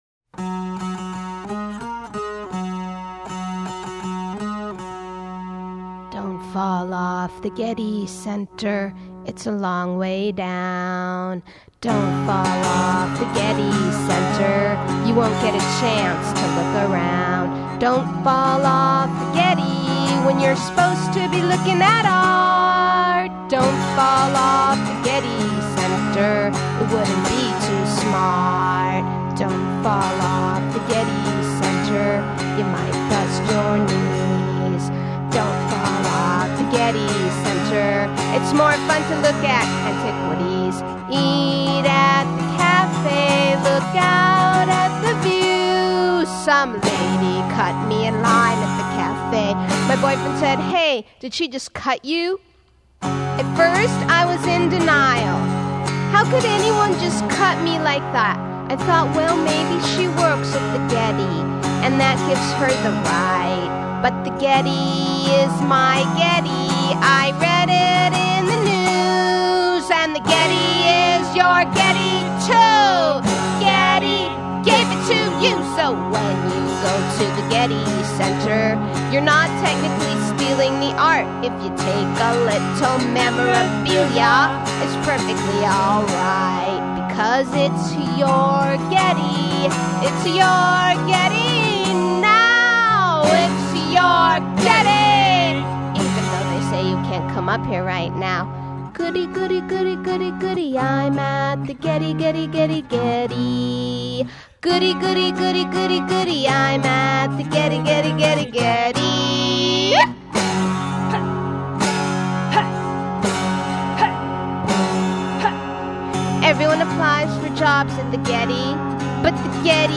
folk punk duo